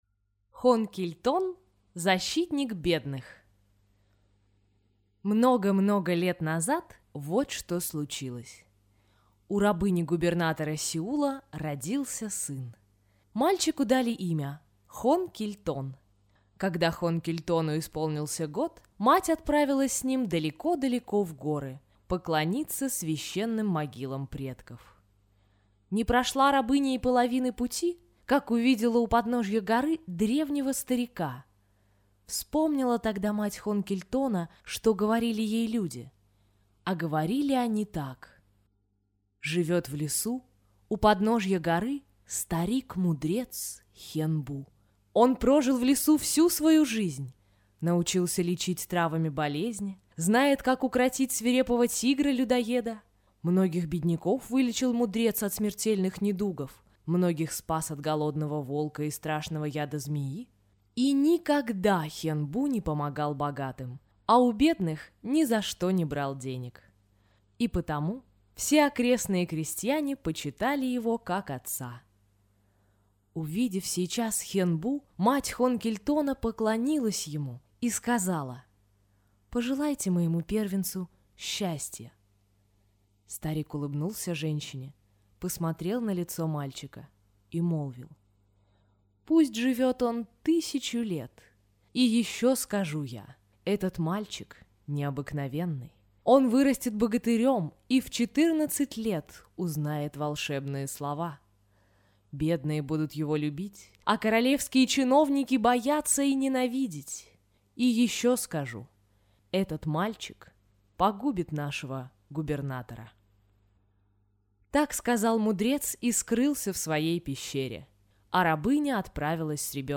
Хон Киль Тон- защитник бедных - корейская аудиосказка